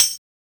BTTRF - Tamb.wav